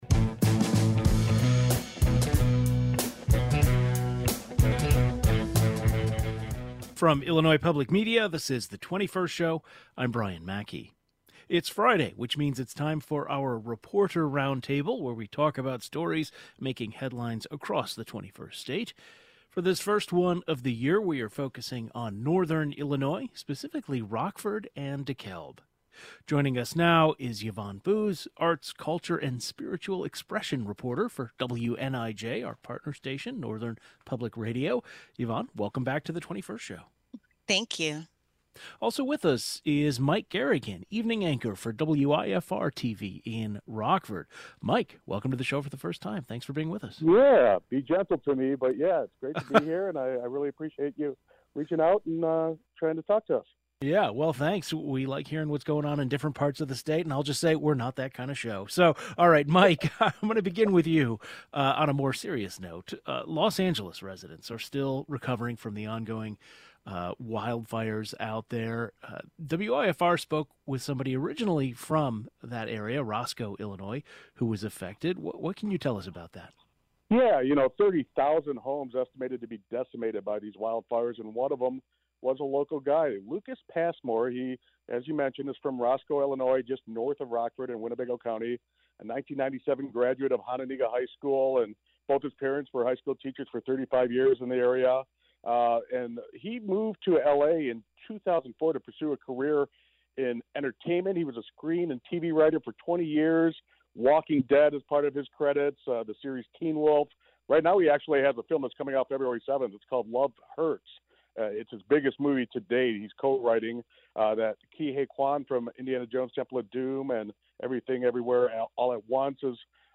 Our Friday Reporter Roundtable discusses stories making headlines across the state. For this first roundtable of 2025, we are focusing on northern Illinois, specifically Rockford and DeKalb.